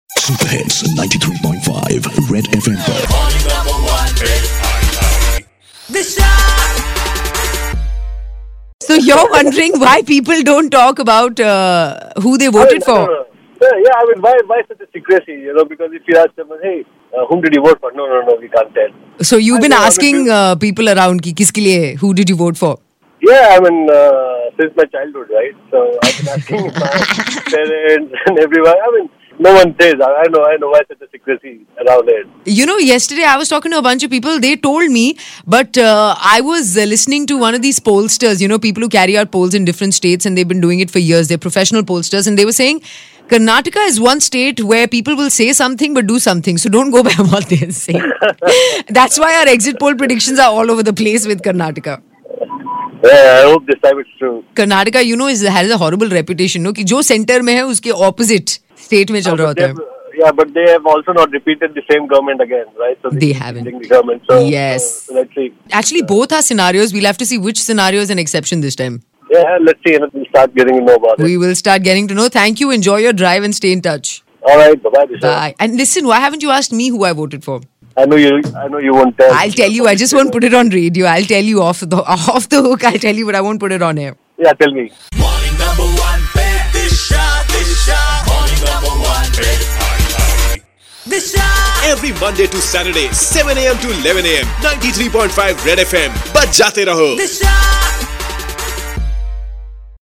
A listener tells why people dont tell whom did they vote